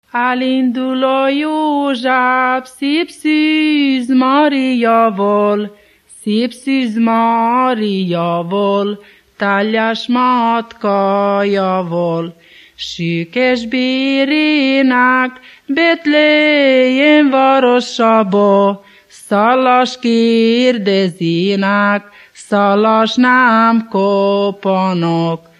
Moldva és Bukovina - Moldva - Külsőrekecsin
ének
Műfaj: Karácsonyi köszöntő
Stílus: 5. Rákóczi dallamkör és fríg környezete
Szótagszám: 7.6.7.6
Kadencia: 5 (b3) 2 1